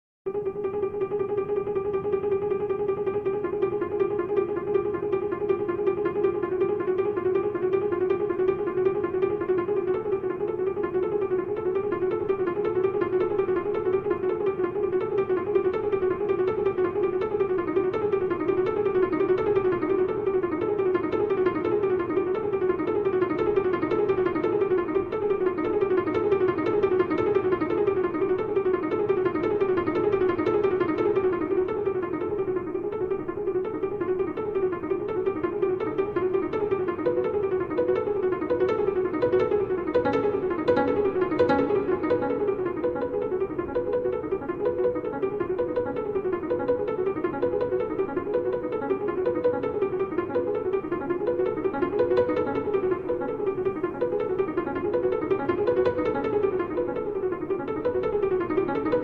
Klavierstück